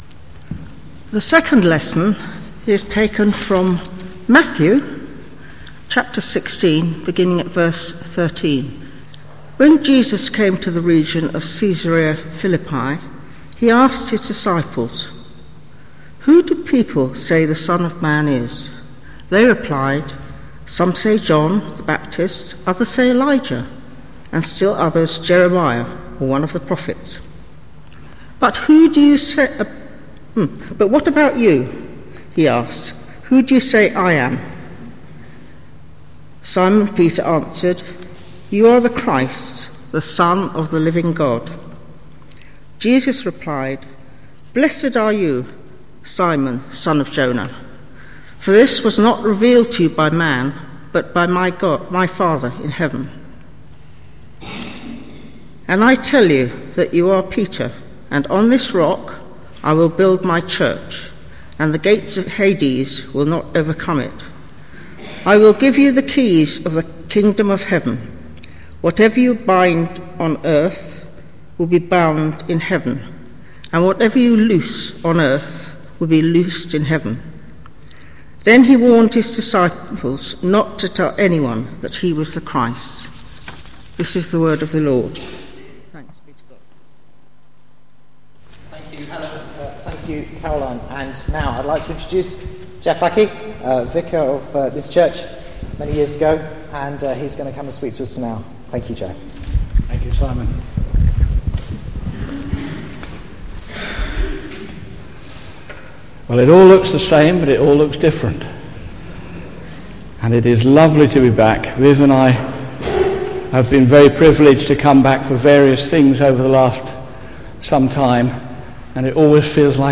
Centenary 2011 sermons – Matthew 16:13-20